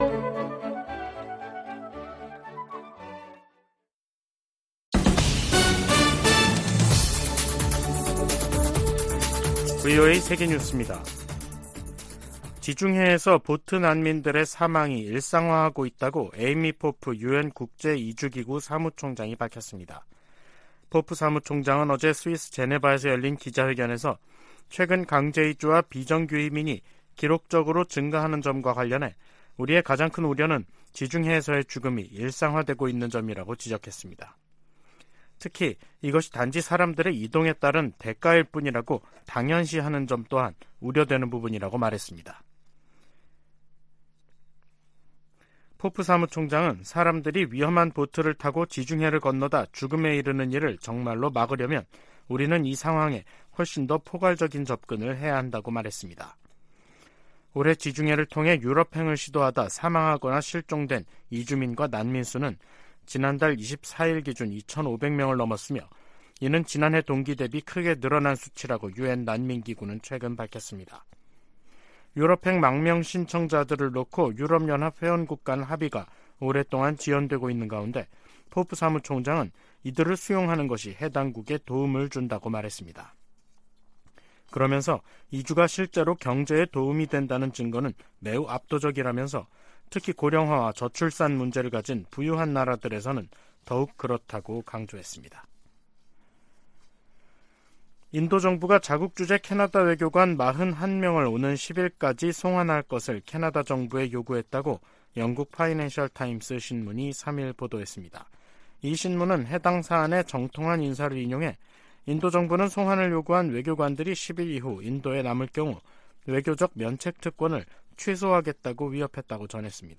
VOA 한국어 간판 뉴스 프로그램 '뉴스 투데이', 2023년 10월 3일 3부 방송입니다. 미 국무부는 중국이 대북 영향력을 활용해 북한을 외교로 복귀시켜야 한다고 밝혔습니다. 유엔총회 제1위원회 회의에서 미국은 북한과 러시아 간 무기 거래가 국제 평화에 대한 중대한 위협이라고 지적했습니다. 북한이 군사정찰위성을 세 번째로 쏘겠다고 공언한 10월에 접어들면서 관련국들이 북러 군사 협력 가시화에 촉각을 곤두세우고 있습니다.